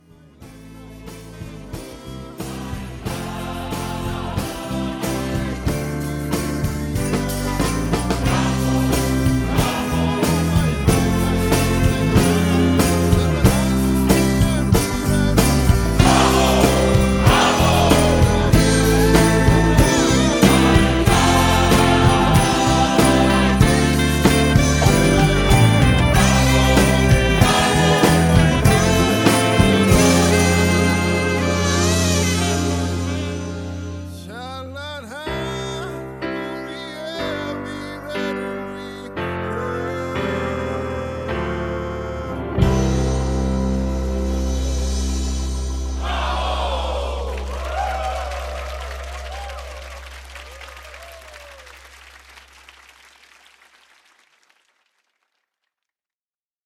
음정 -1키 4:23
장르 가요 구분 Voice MR